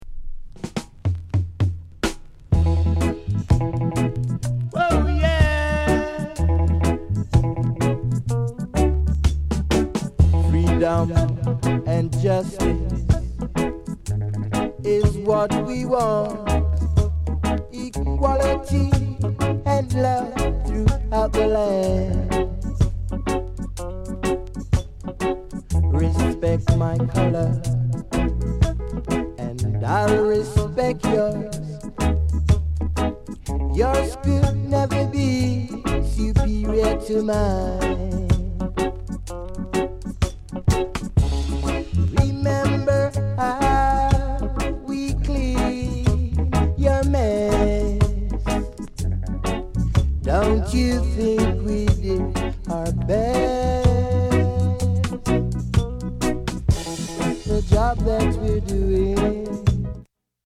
ROOTS